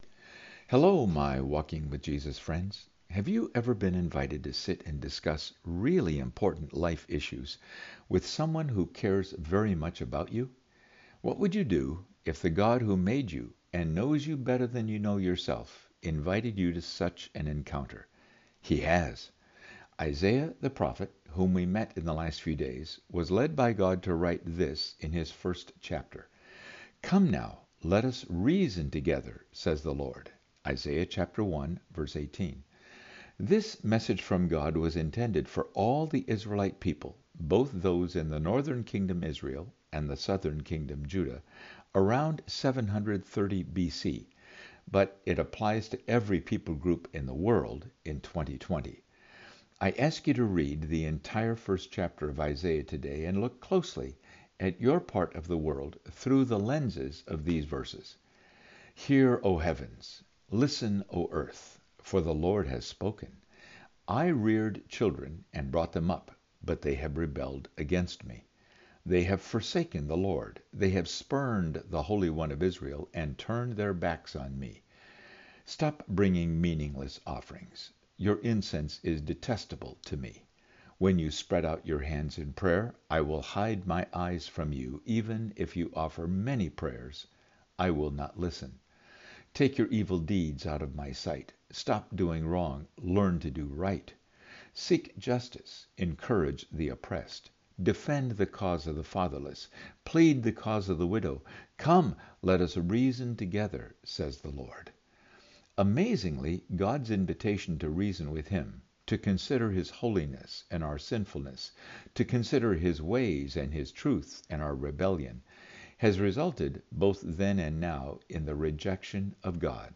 Today, I’m going to ask you to watch & listen to a clip from a message I gave recently which calls us to reason with God about the “Test of Freedom”. How are you and I handling the God given FREEDOM we have?